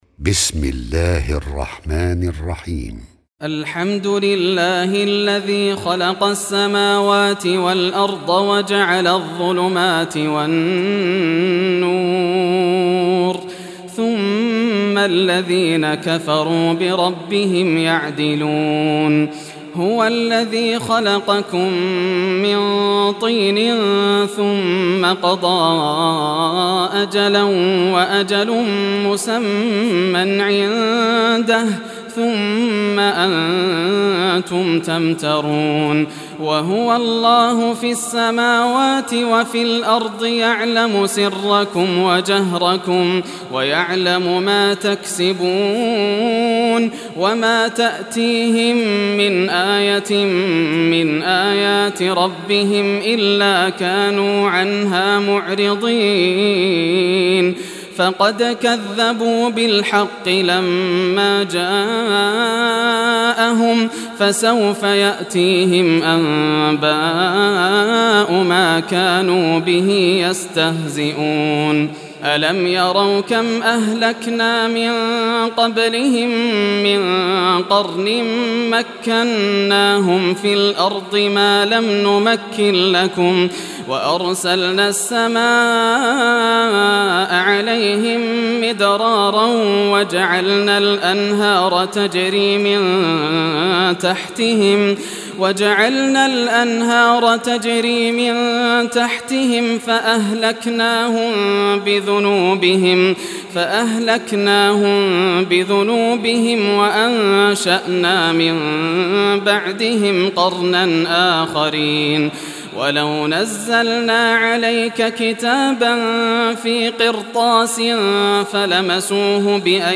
Surah Al-Anam Recitation by Sheikh Yasser Dosari
Surah Al-Anam, listen or play online mp3 tilawat / recitation in Arabic in the beautiful voice of Sheikh Yasser al Dosari.